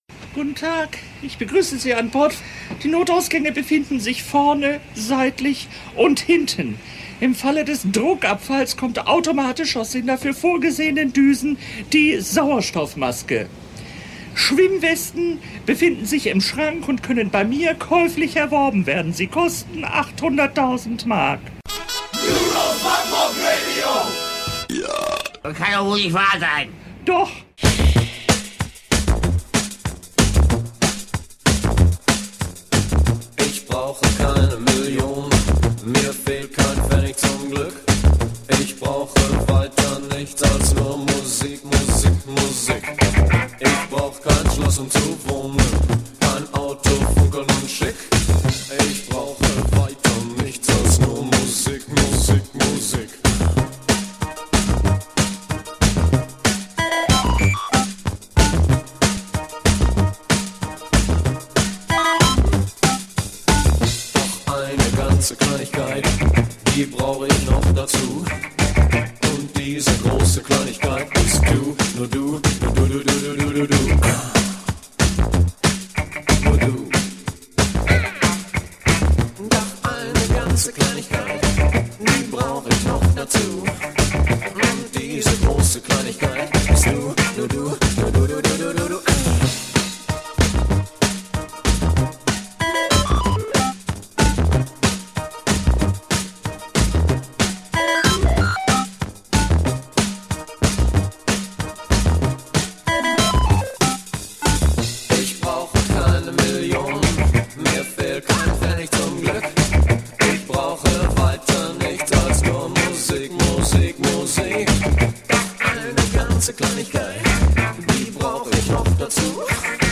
Und ziemlich viele Coverversionen heute…